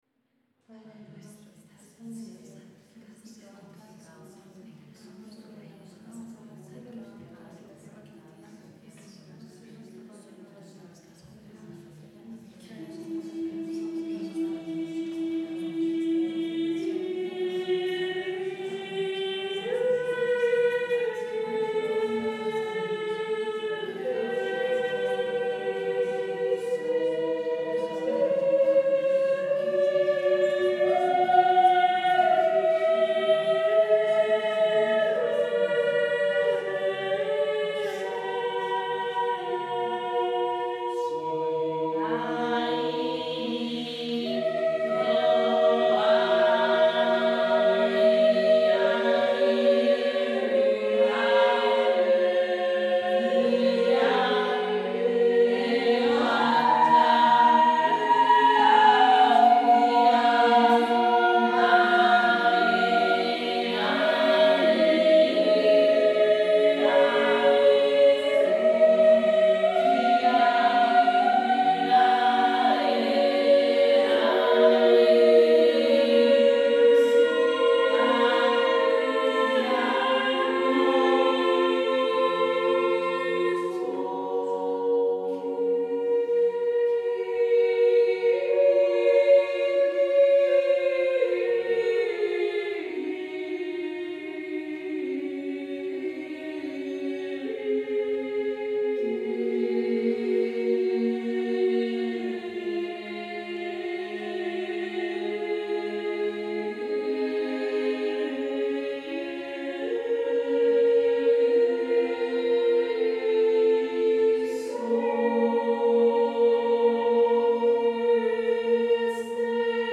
Kyrie Mwono para coro femenino